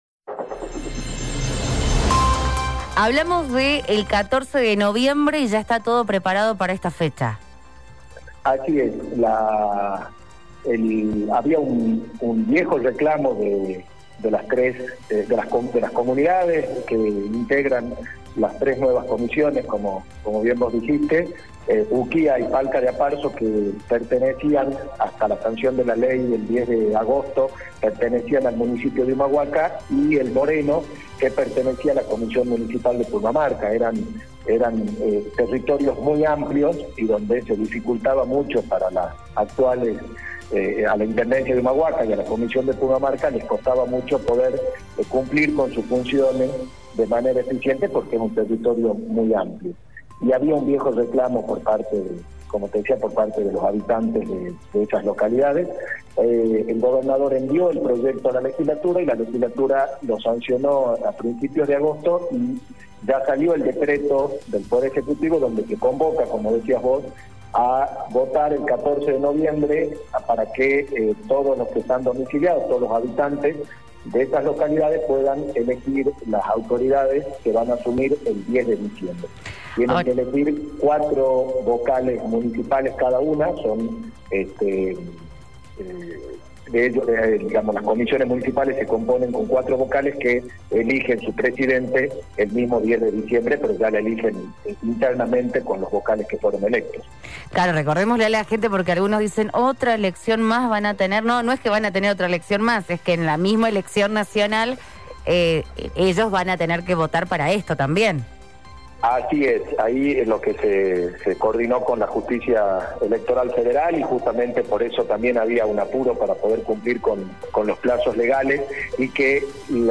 En dialogo con Javiar Gronda, secretario de Relaciones y Asuntos Municipales, confirmó que el 14 de noviembre se elegirán las nuevas autoridades y por primera vez los miembros de estas tres comisiones municipales, Uquía, El Moreno y Palca de Aparzo.
Javier Gronda - Secretario de Relaciones y Asuntos Municipales